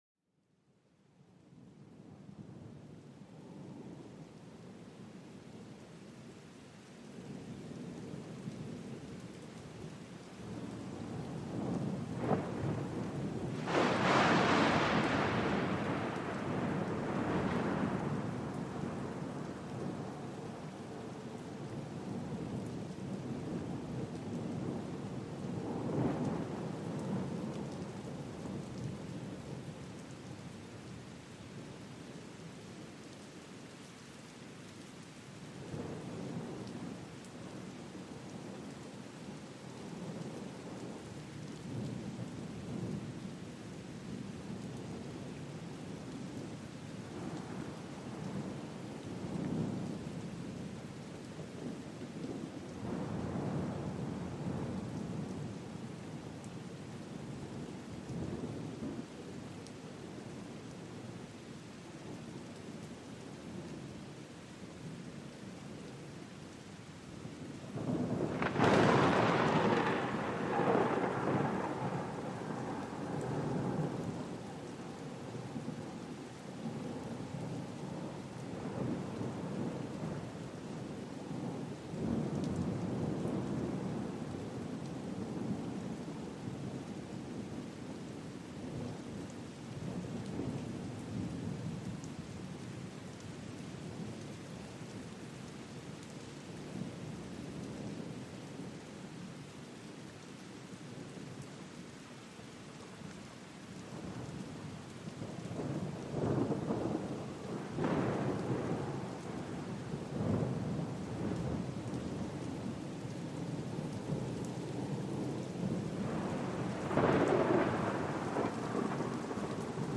Plongez au cœur d'une nature déchaînée où l'orage déploie sa force, entre éclairs illuminant le ciel et tonnerre roulant avec majesté. Cet épisode vous invite à ressentir la puissance vibrante de l'orage, véritable symphonie de sons brut et fascinant, où chaque coup de tonnerre résonne comme un battement de cœur de la terre.